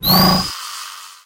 Звук подтверждения электронной брони